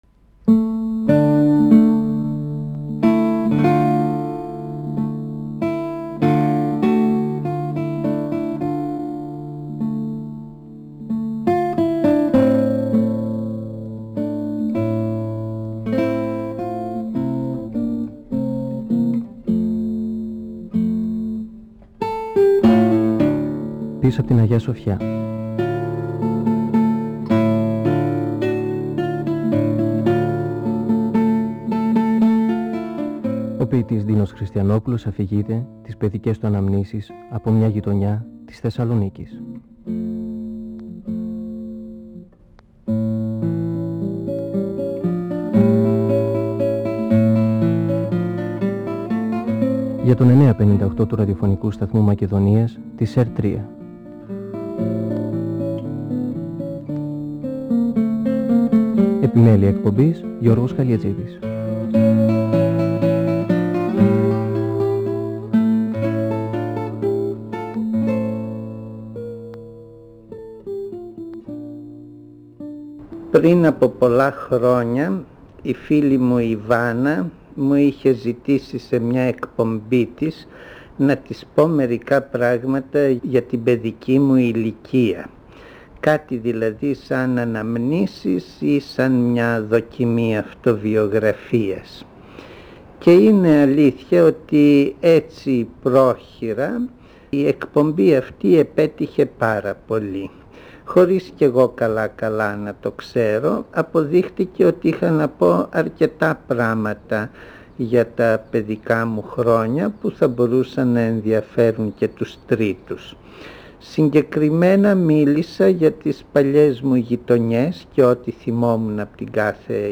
(Εκπομπή 4η) Ο ποιητής Ντίνος Χριστιανόπουλος (1931-2020) μιλά για τις αναμνήσεις του από μια παλιά γειτονιά της Θεσσαλονίκης, λίγο πριν και κατά τη διάρκεια του ελληνοϊταλικού πολέμου.